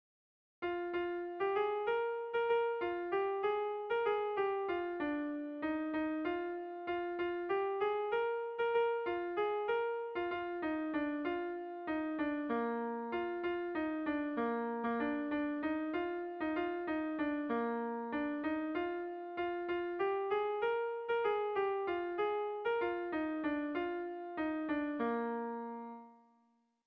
Melodías de bertsos - Ver ficha   Más información sobre esta sección
Kontakizunezkoa
A1A2BA2